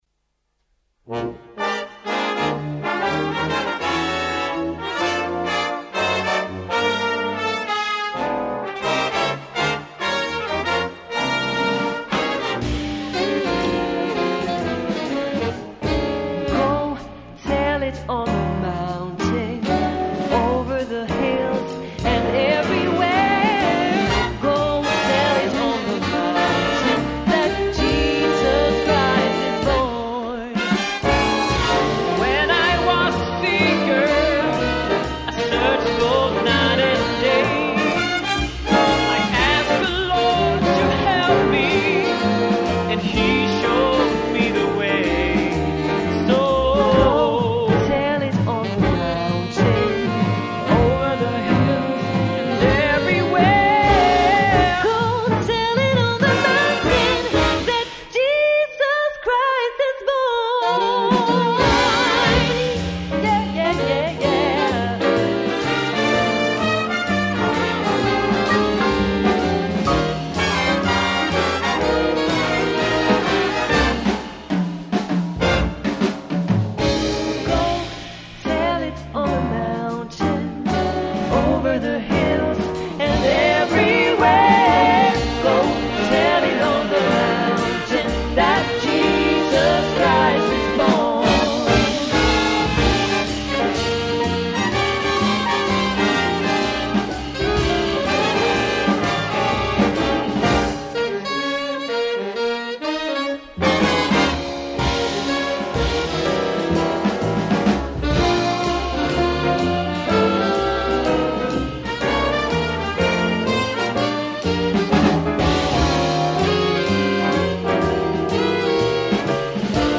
Bas           Gitarr          Piano          Trumset